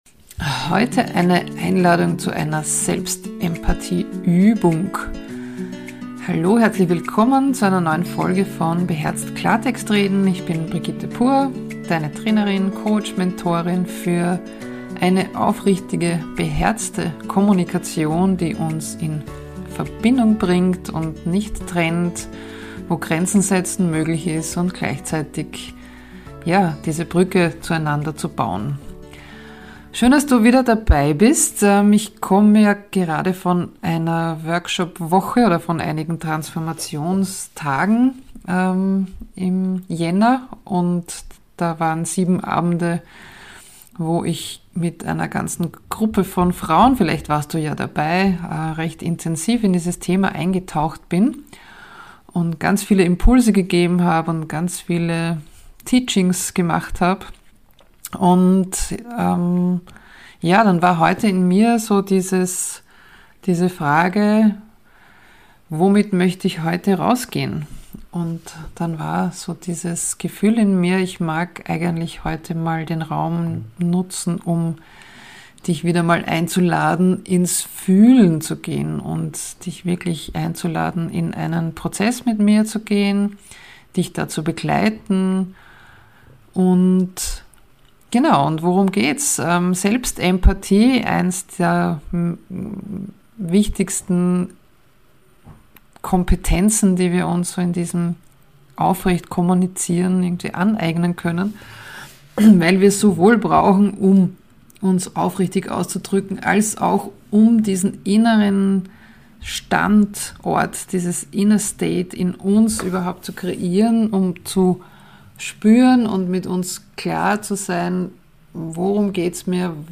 | Selbstempathie statt Stress vor 2 Monaten Geführte Übung für mehr Klarheit und Ruhe. 17 Minuten 0 0 0 0 0 0 Podcast Podcaster beherzt Klartext reden.